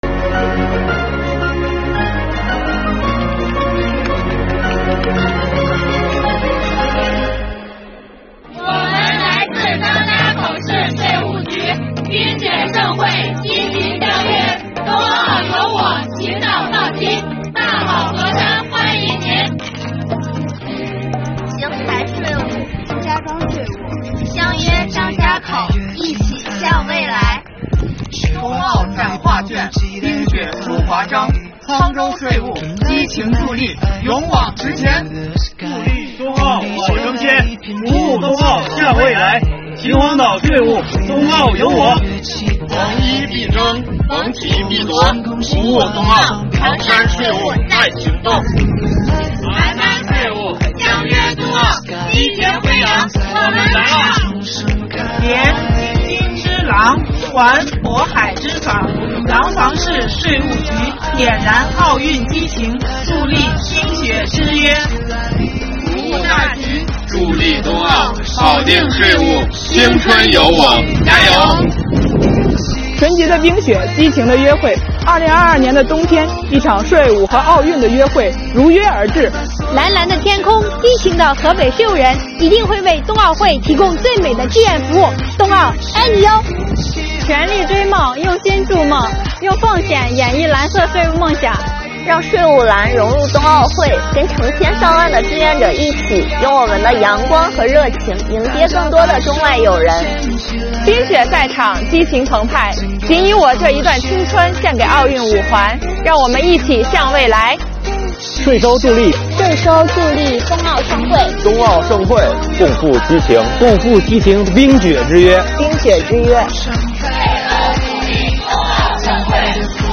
一起来听听他们是怎么说的吧